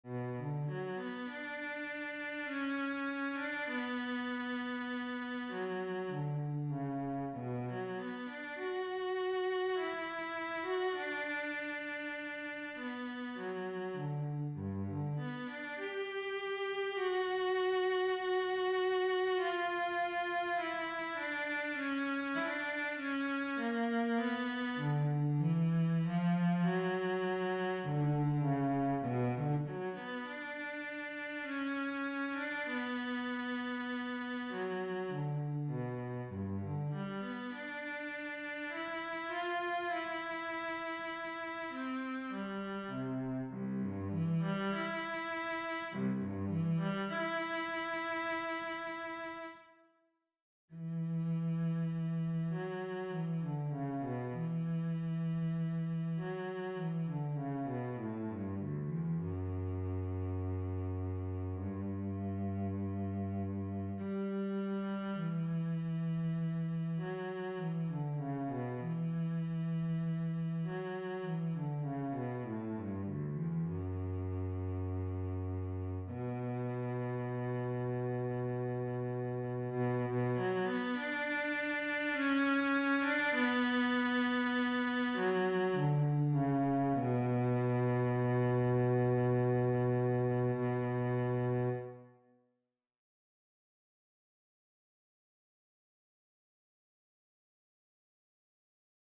Voicing: Cello and Piano